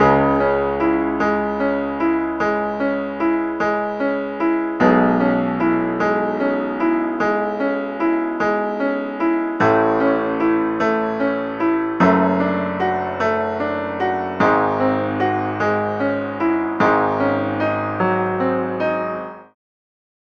Iowa Piano縺ｯ辟｡譁吶〒菴ｿ縺医ｋ繝斐い繝朱浹貅舌〒縺吶Iowa螟ｧ蟄ｦ縺ｧ蜿朱鹸縺輔ｌ縺溘せ繧ｿ繧､繝ｳ繧ｦ繧ｧ繧､繧偵し繝ｳ繝励Μ繝ｳ繧ｰ縺励※縺翫ｊ縲騾乗取─縺ｮ縺ゅｋ邏逶ｴ縺ｪ髻ｿ縺阪′讌ｽ縺励ａ縺ｾ縺吶
Iowa Piano縺ｮ繧ｵ繝ｳ繝励Ν髻ｳ貅